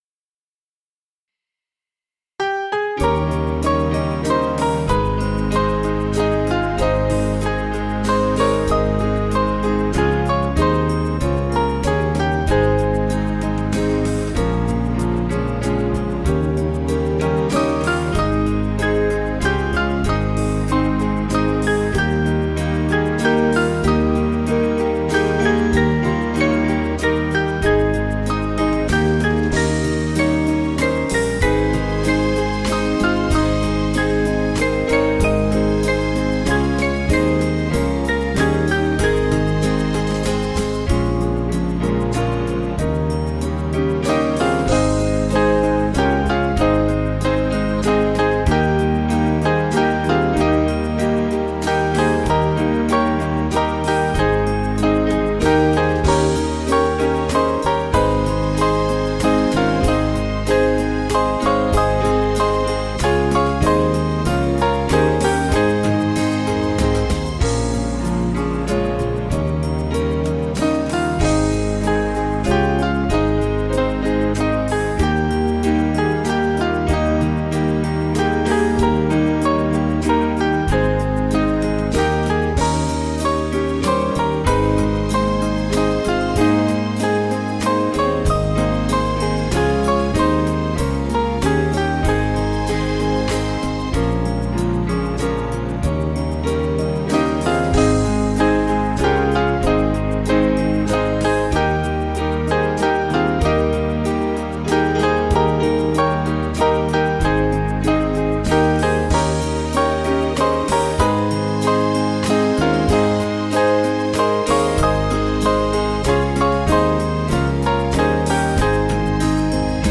Small Band
(CM)   7/Ab 486.9kb